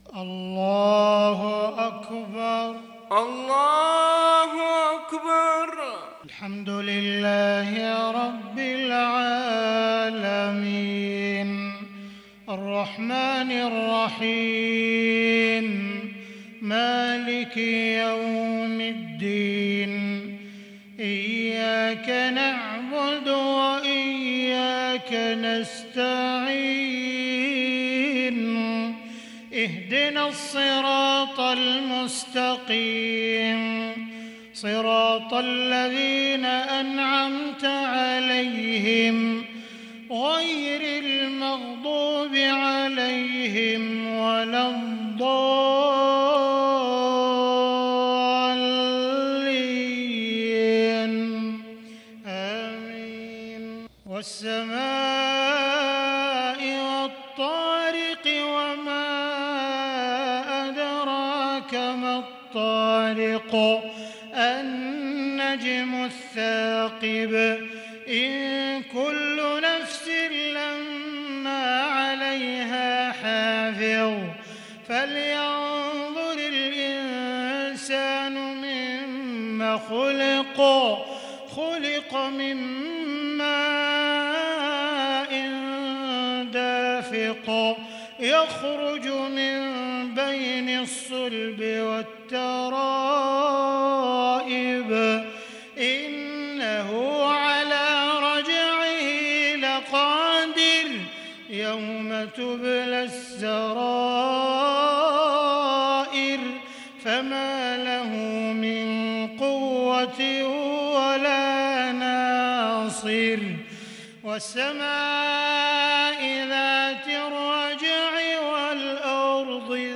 صلاة المغرب للشيخ عبدالرحمن السديس 3 ذو القعدة 1442 هـ
تِلَاوَات الْحَرَمَيْن .